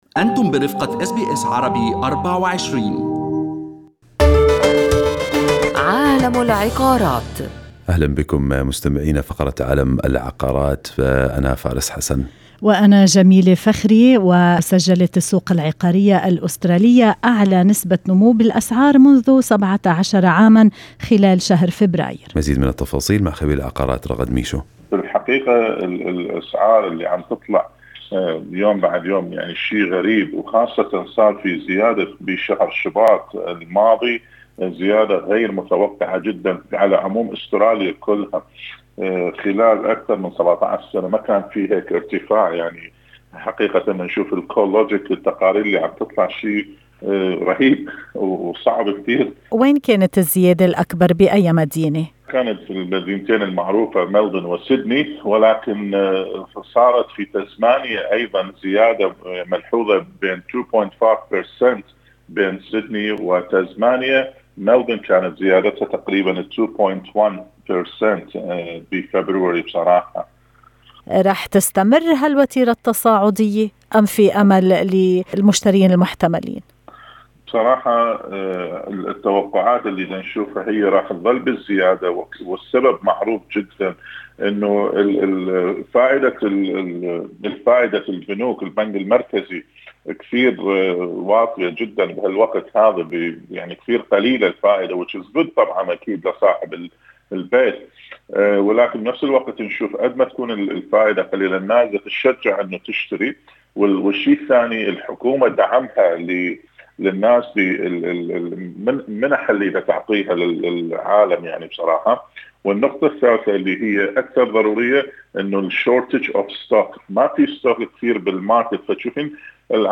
كل ما ورد في هذه السطور وفي المقابلة الصوتية المرفقة بالرابط هو على سبيل المعلومات العامة ويرجى استشارة الأخصائيين للحول على نصائح خاصة بكم.